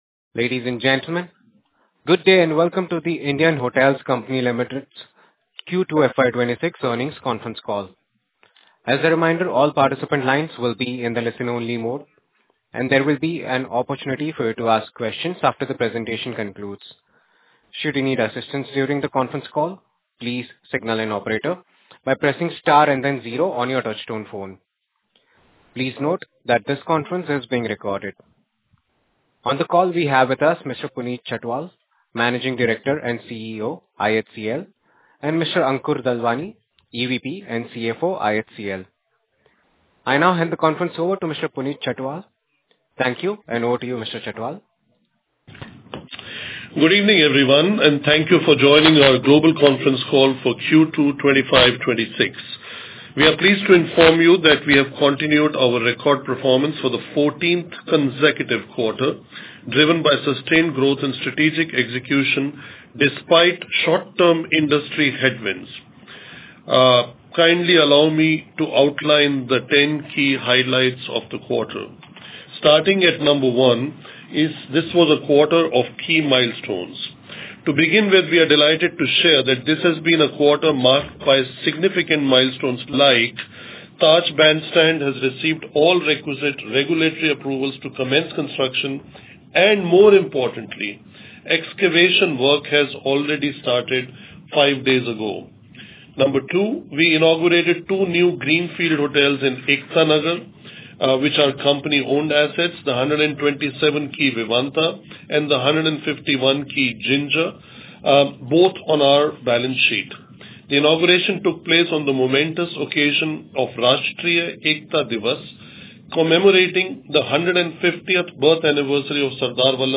ihcl-global-earnings-call_q2fy26_recording.mp3